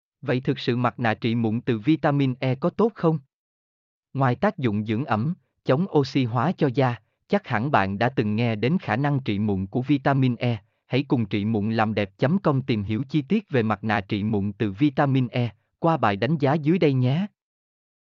mp3-output-ttsfreedotcom-1-1.mp3